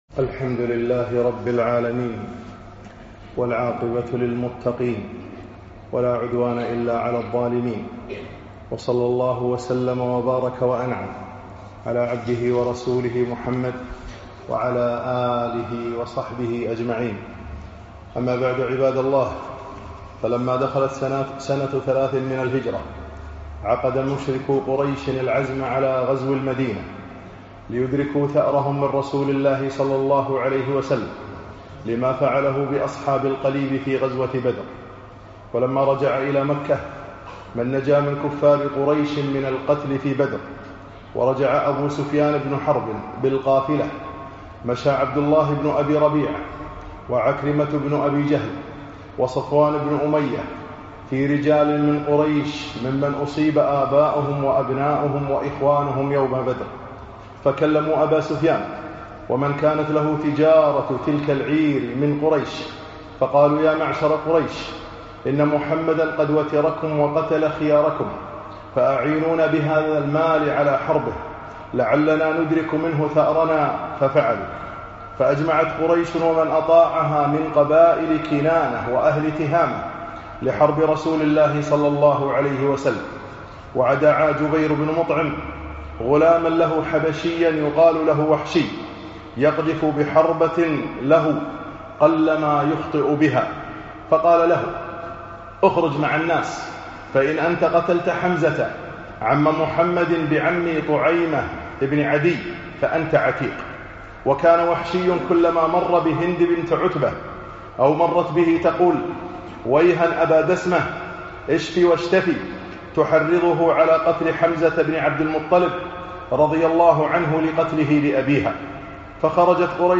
خطب السيرة النبوية 15